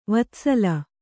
♪ vatsala